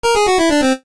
jingles-retro_10.ogg